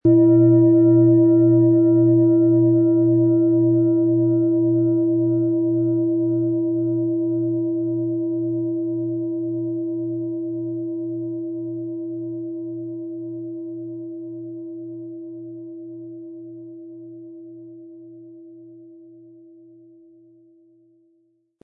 Von Hand getriebene Schale mit dem Planetenton Hopi-Herzton.
• Tiefster Ton: Mond
Unter dem Artikel-Bild finden Sie den Original-Klang dieser Schale im Audio-Player - Jetzt reinhören.
PlanetentöneHopi Herzton & Mond
MaterialBronze